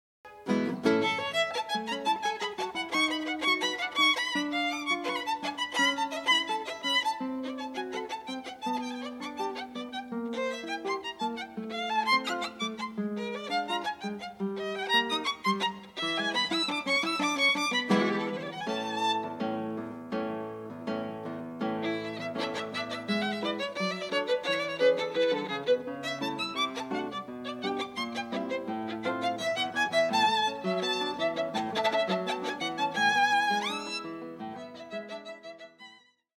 Musical Performances